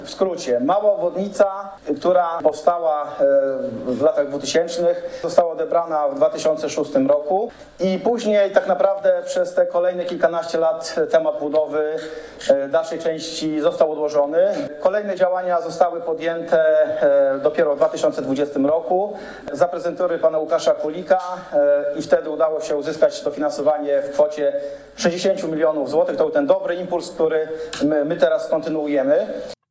Prezydent miasta Paweł Niewiadomski nazwał inwestycję „historyczną” i przypomniał długą historię walki o powstanie południowej obwodnicy.